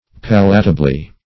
Search Result for " palatably" : Wordnet 3.0 ADVERB (1) 1. in a palatable way ; The Collaborative International Dictionary of English v.0.48: Palatably \Pal"a*ta*bly\, adv.